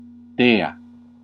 Ääntäminen
Synonyymit dīva Ääntäminen Classical: IPA: /ˈde.a/ Haettu sana löytyi näillä lähdekielillä: latina Käännös Ääninäyte 1. divinité {f} 2. dieu {m} France 3. déesse {f} Suku: f .